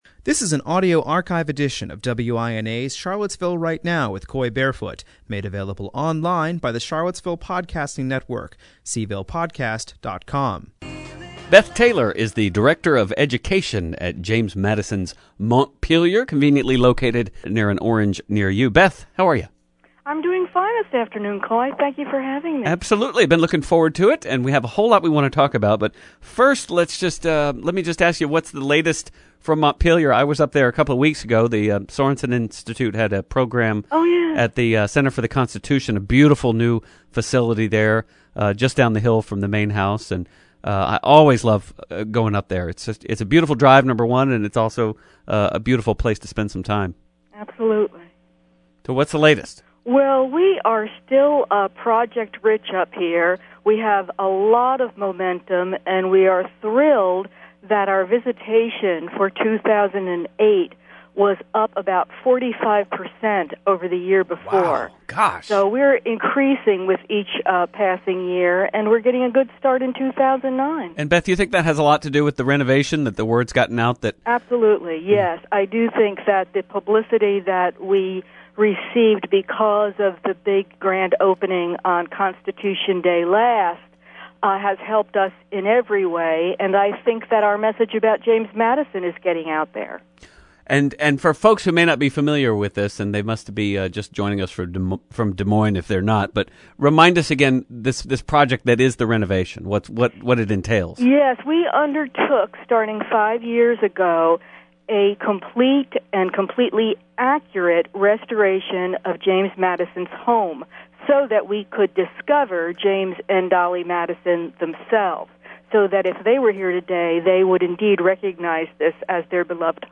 The meeting was held at the Senior Center in Charlottesville. Following the presentation, questions were taken from the audience.